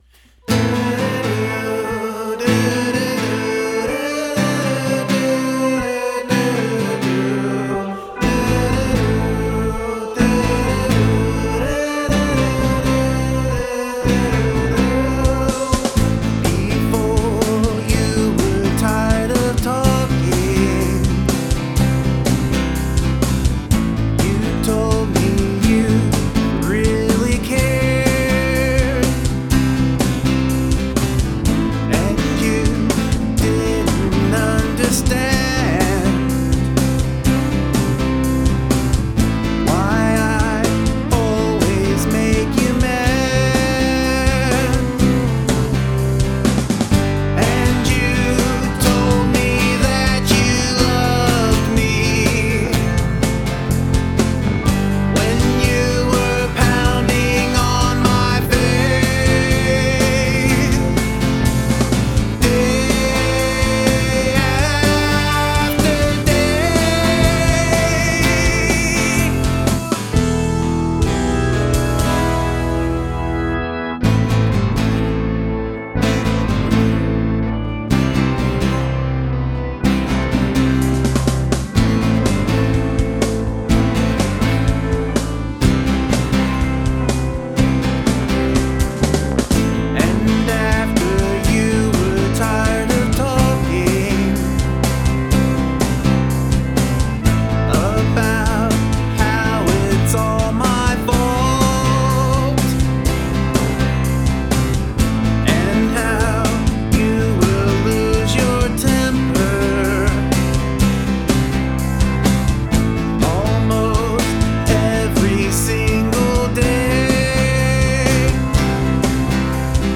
Lyric consists of only one sentence.